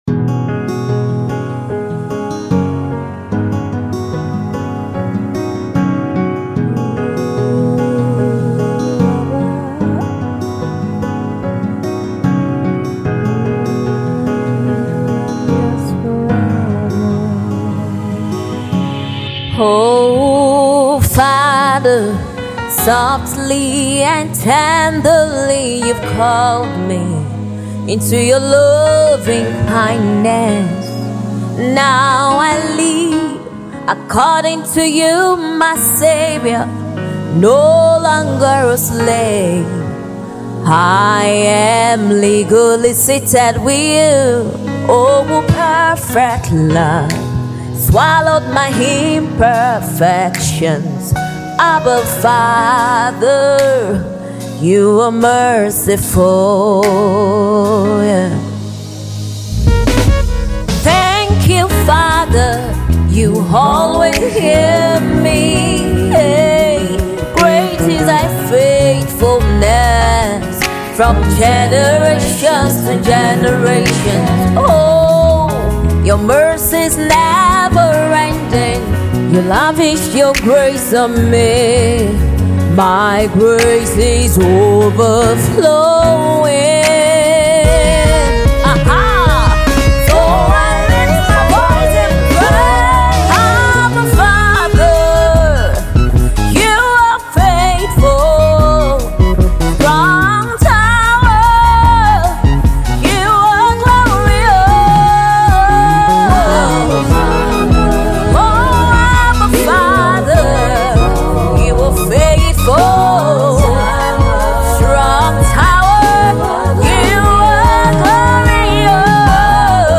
Nigerian Gospel music recording artiste
songwriter and vocal powerhouse